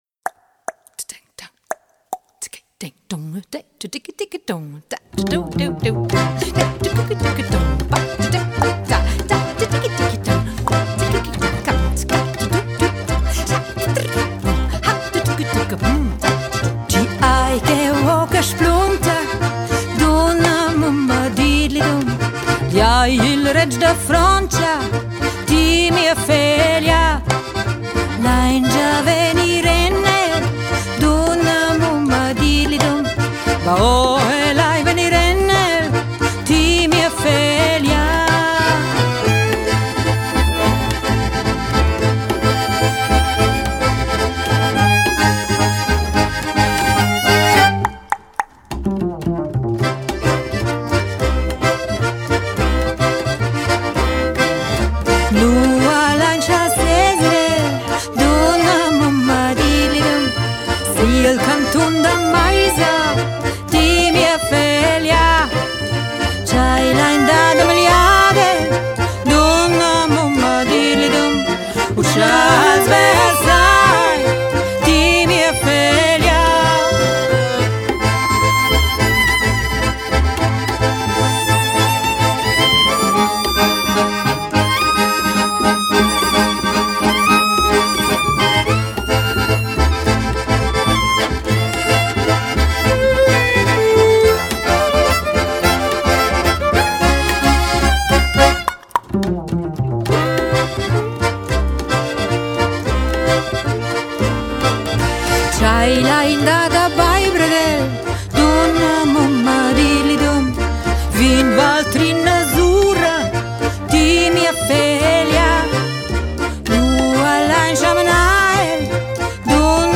A traditional song revived
give a new twist to traditional Romansh song.